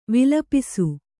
♪ vilapisu